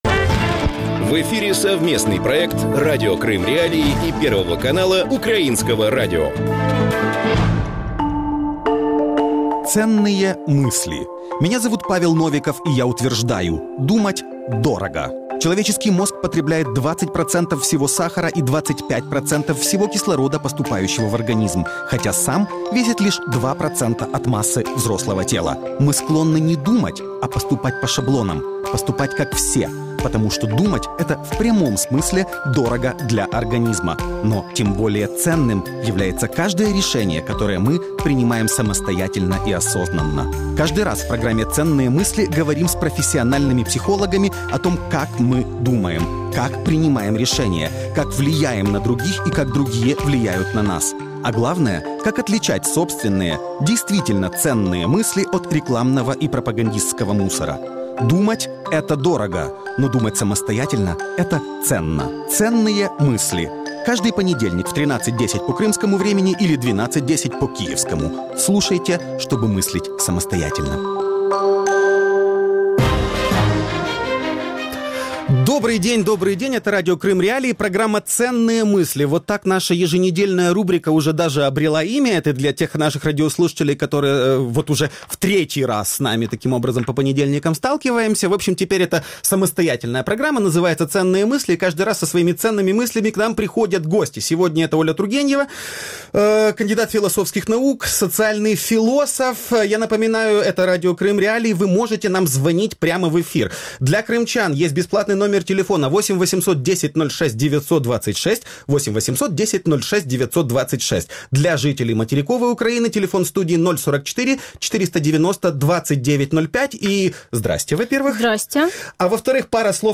Свой среди чужих – чужой среди своих. Интервью